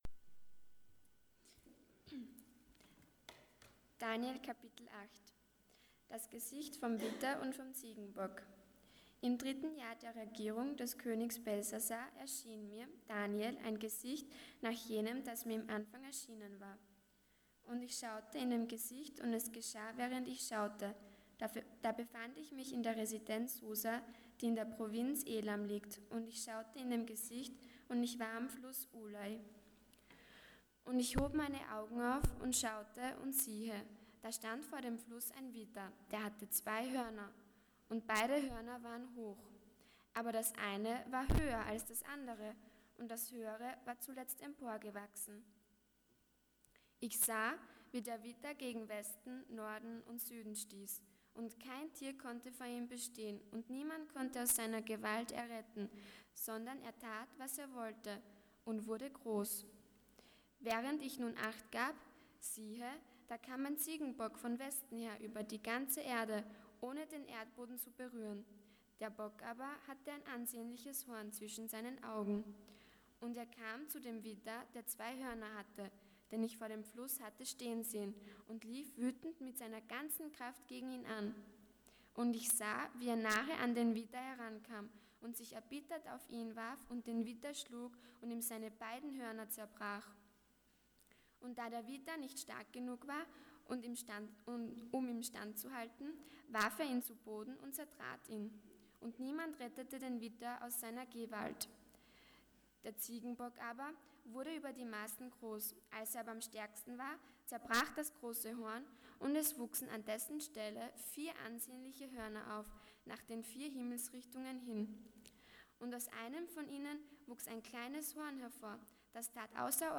Passage: Daniel 8:1-27 Dienstart: Sonntag Morgen